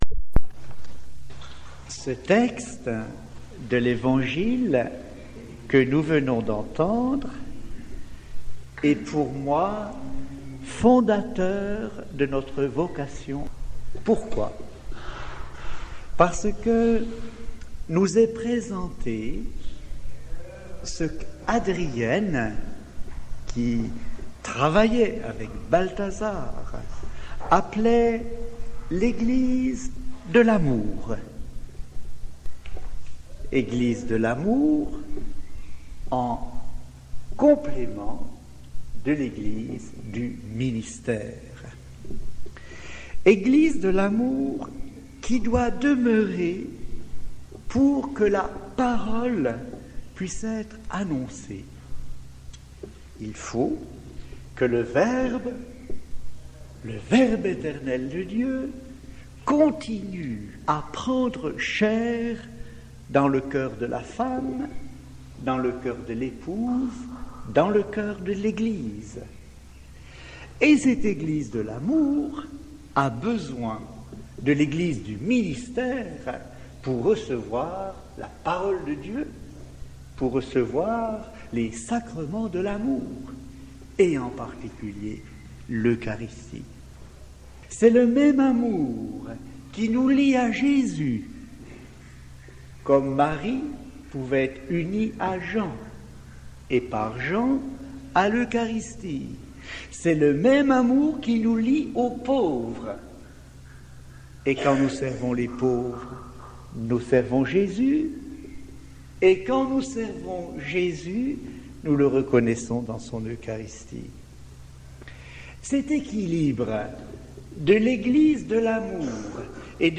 homelie_du_3_juin_2006.mp3